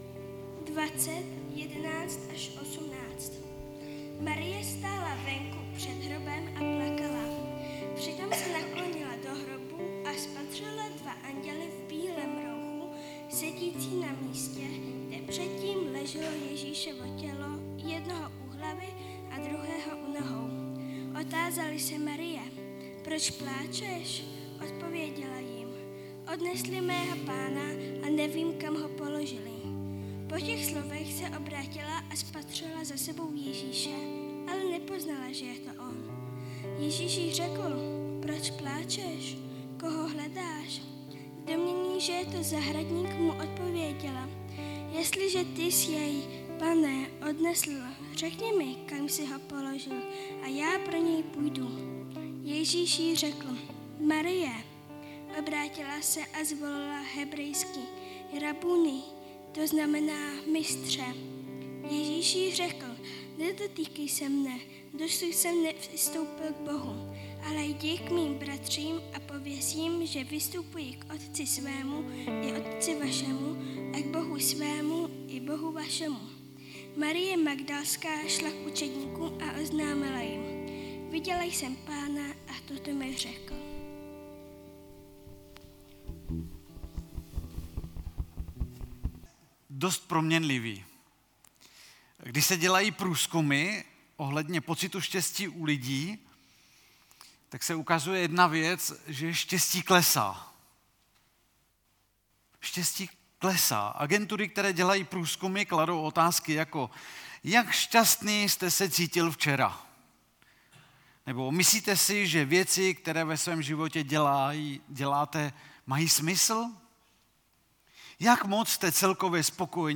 Velikonoční bohoslužba